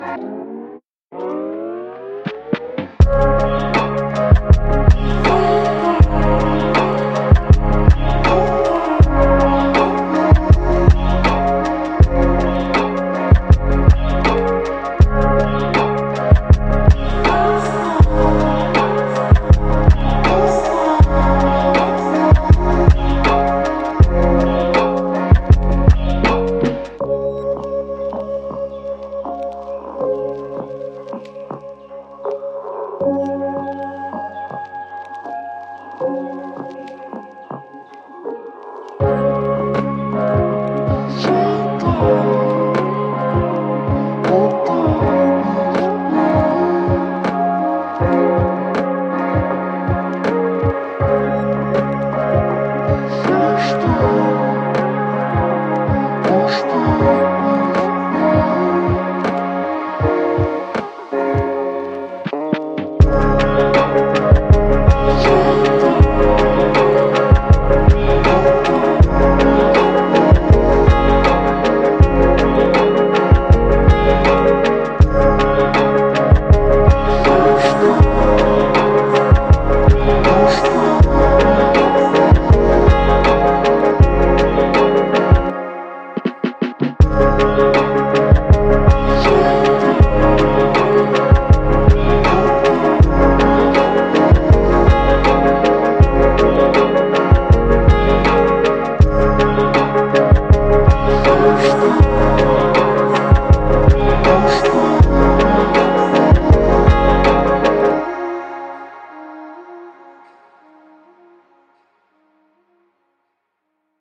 Ещё Vocal Lo-Fi
Очаровательная мелодия для романтической сцены